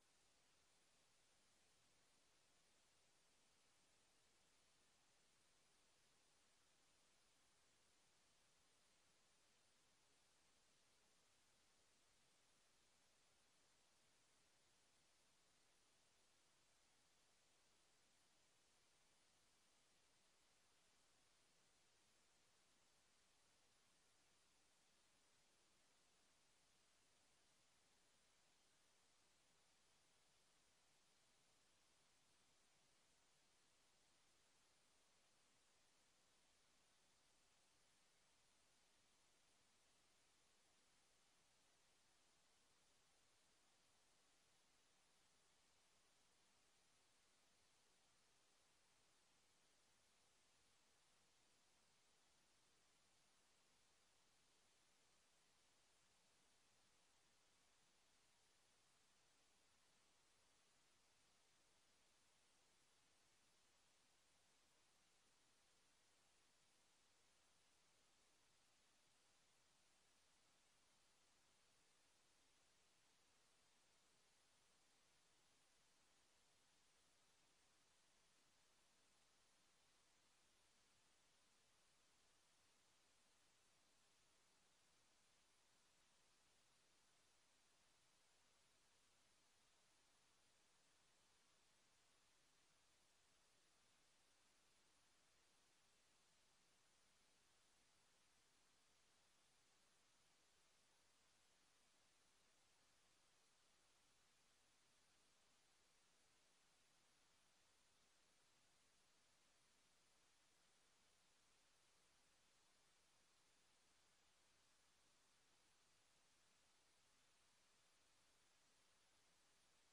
Commissie Burger en bestuur 16 januari 2025 19:30:00, Gemeente Ouder-Amstel
Download de volledige audio van deze vergadering